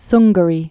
(snggə-rē)